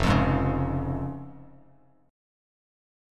Fm#5 chord